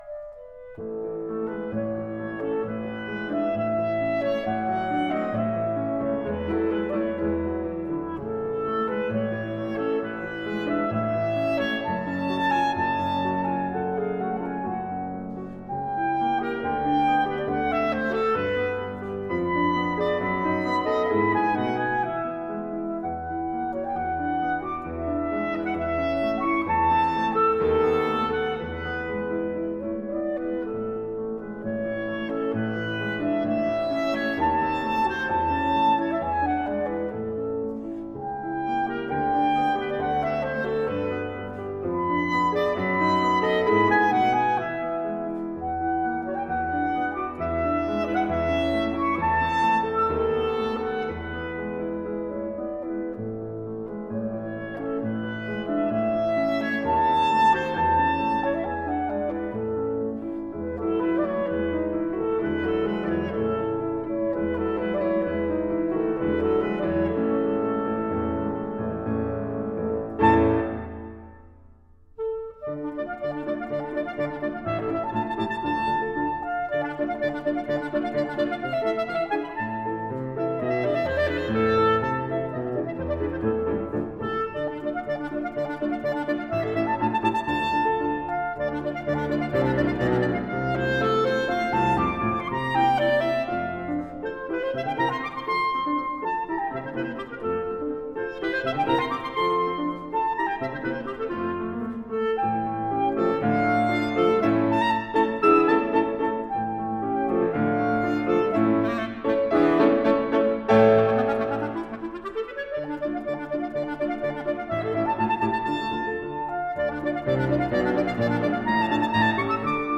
Franz Schubert (1797-1828): Donata Arpeggione for clarinet (originally for guitarre d’amour) and piano D.821 in A minor. III. Allegretto.
clarinet.
piano.